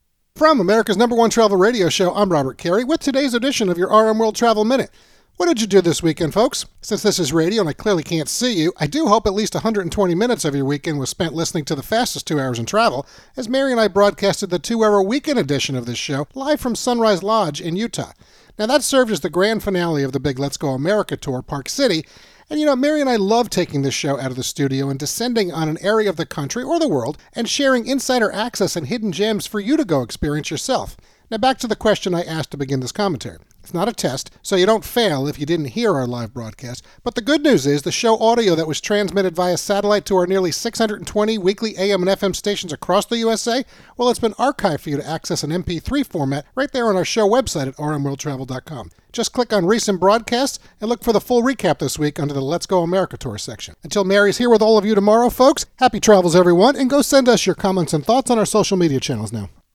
America's #1 Travel Radio Show